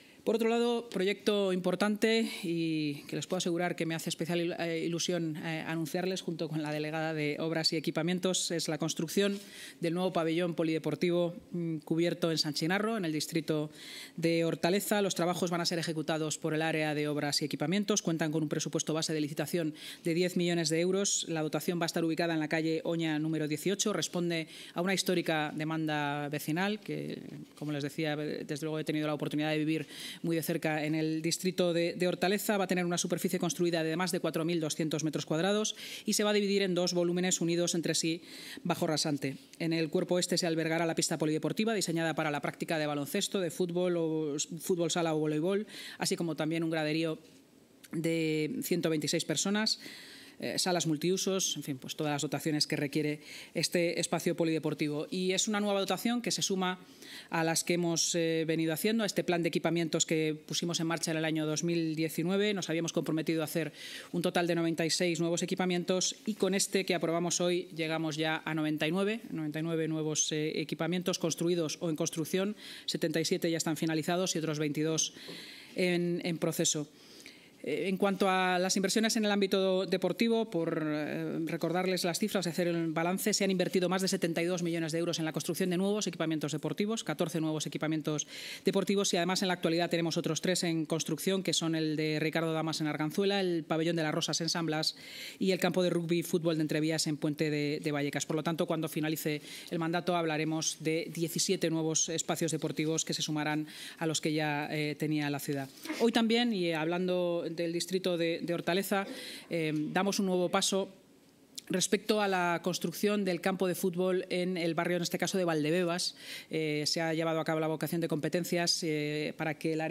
Así lo ha anunciado en rueda de prensa la vicealcaldesa y portavoz municipal, Inma Sanz, tras la reunión semanal de la Junta de Gobierno.